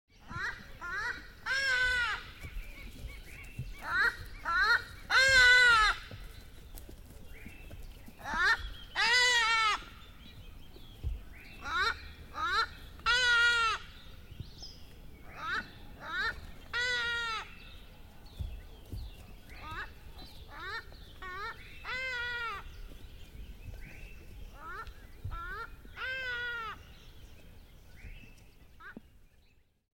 دانلود آهنگ پرنده 17 از افکت صوتی انسان و موجودات زنده
دانلود صدای پرنده 17 از ساعد نیوز با لینک مستقیم و کیفیت بالا
جلوه های صوتی